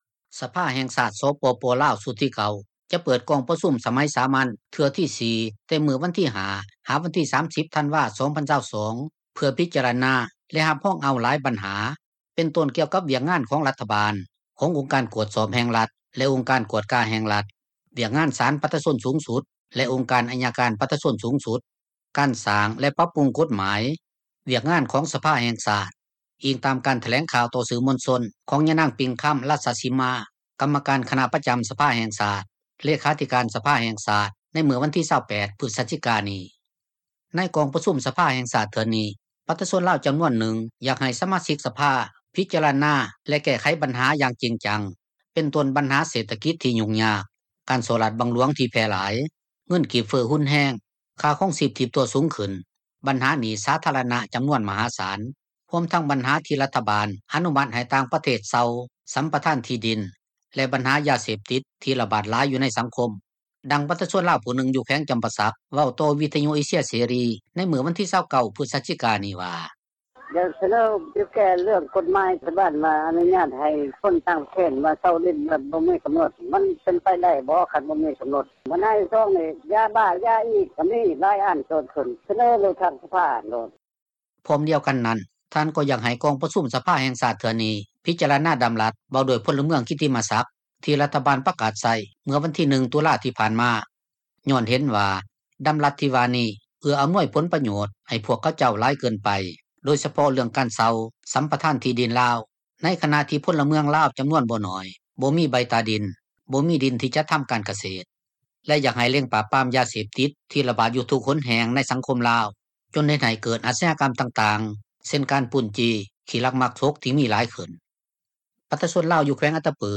ດັ່ງປະຊາຊົນລາວ ຜູ້ນຶ່ງ ຢູ່ແຂວງຈໍາປາສັກ ເວົ້າຕໍ່ວິທຍຸ ເອເຊັຽເສຣີ ໃນມື້ວັນທີ 29 ພຶສຈິການີ້ວ່າ: